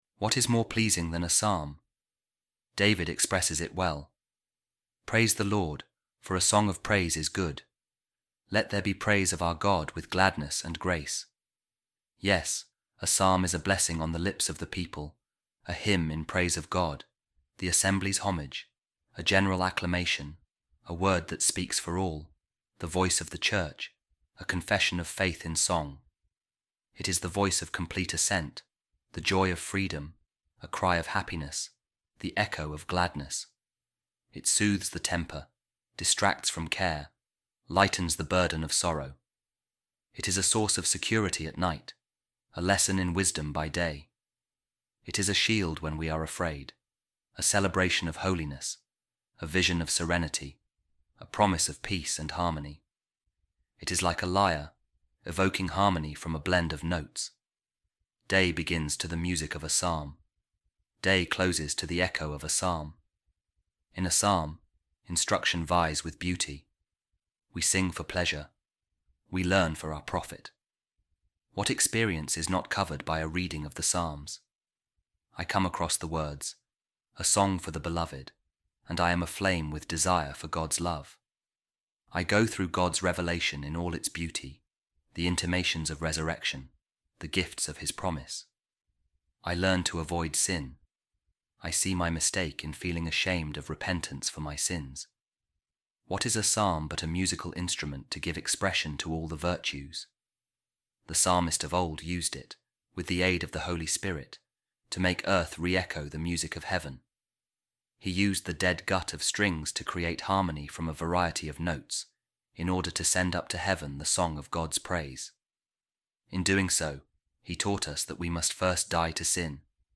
A Reading From The Discourse Of Saint Ambrose On The Psalms | I Will Sing With The Spirit And I Will Sing With The Mind Also